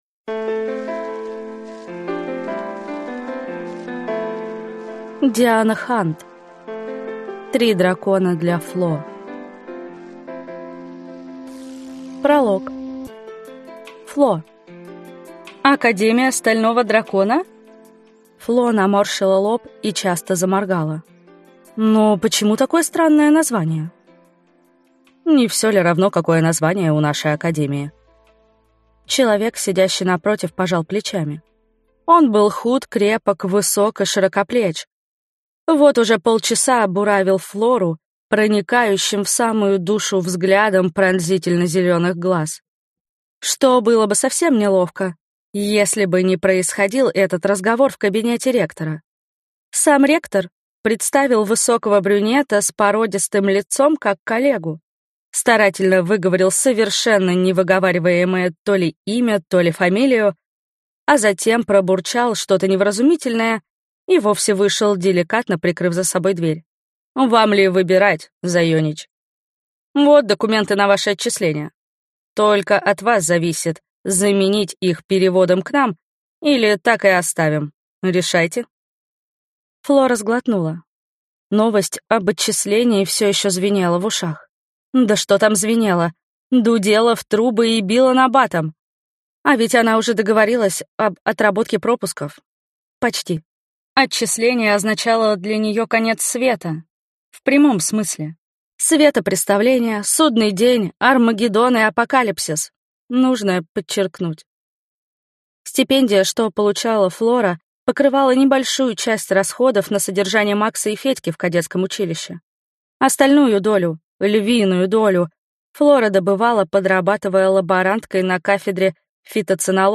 Аудиокнига Три дракона для Фло | Библиотека аудиокниг